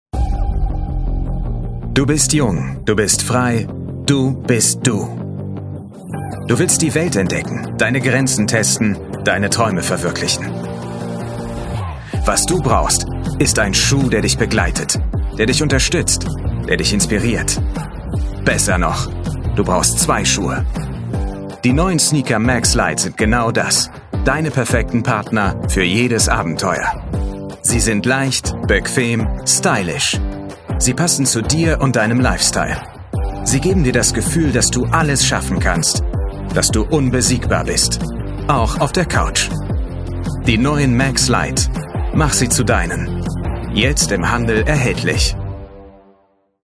Polnisch - TV-Werbung Polnisch - Imagefilm